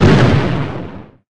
gen_small_explo_02.ogg